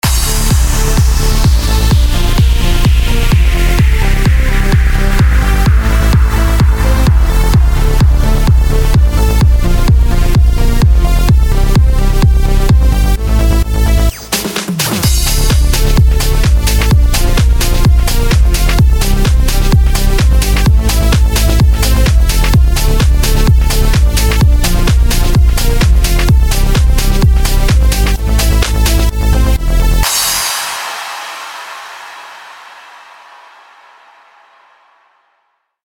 Только что то низов нету